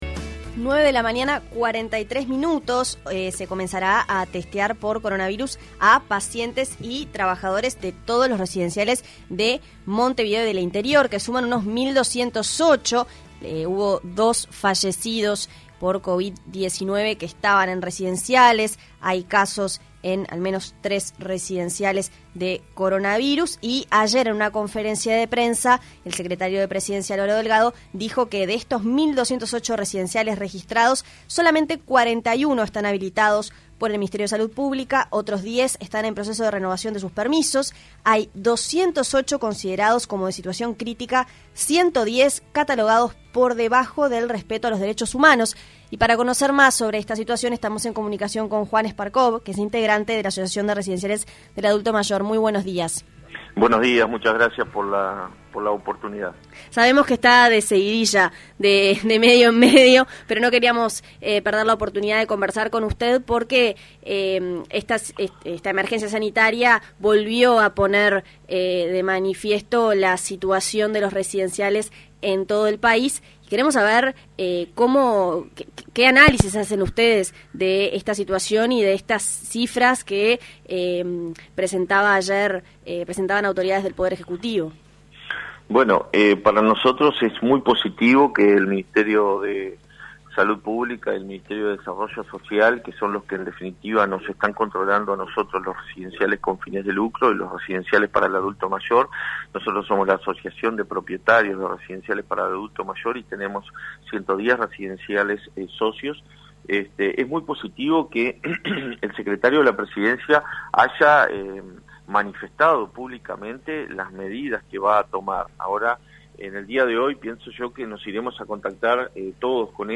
ENTREVISTA-PDE-27420.mp3